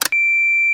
Громовой взрыв звука